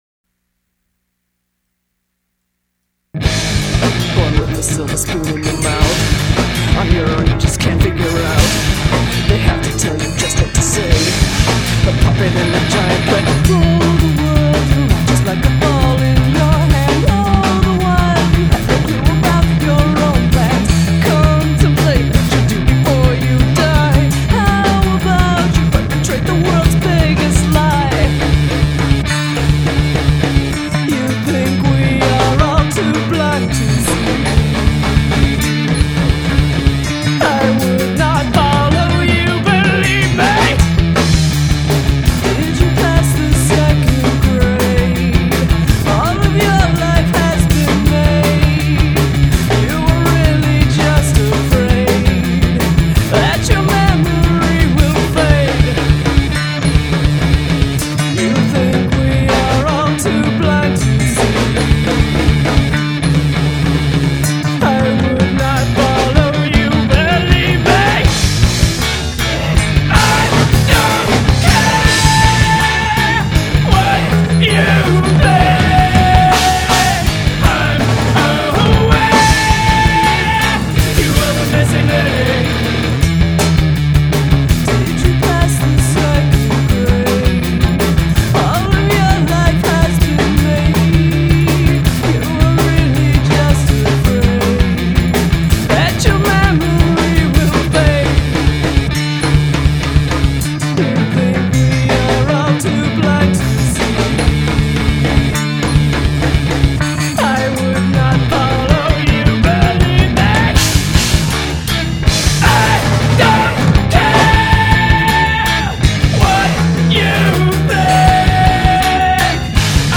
Drums
Vocals, percussion, samples